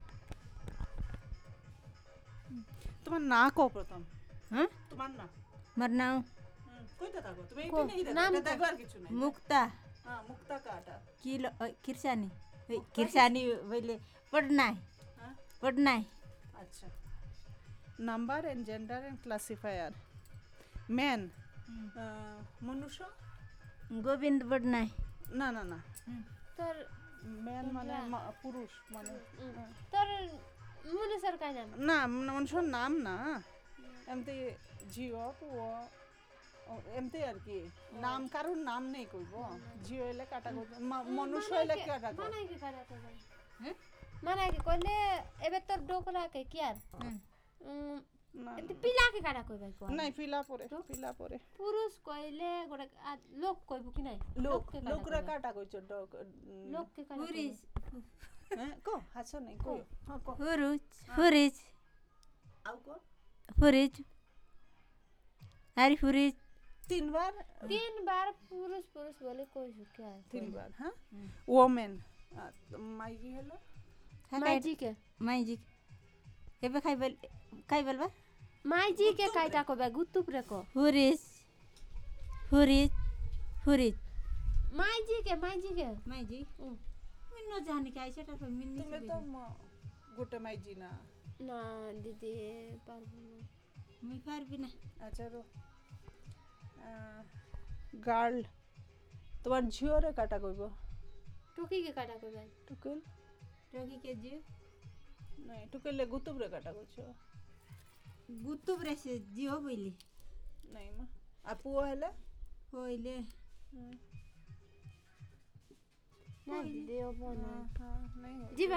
Elicitation of words about number gender and classifiers